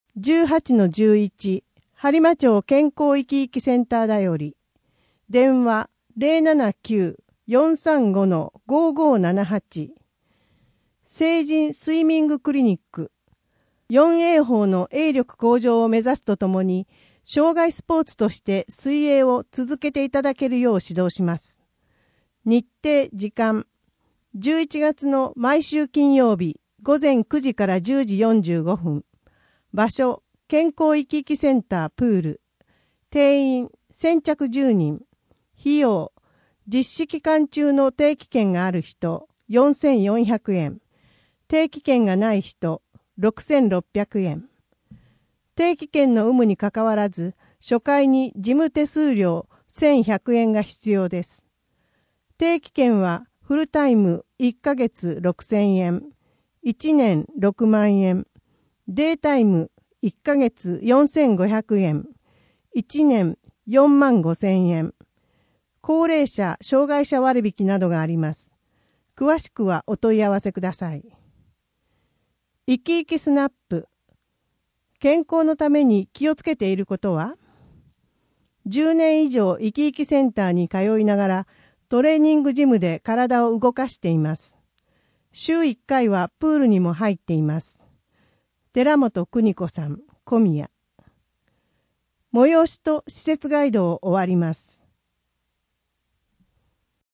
声の「広報はりま」11月号
声の「広報はりま」はボランティアグループ「のぎく」のご協力により作成されています。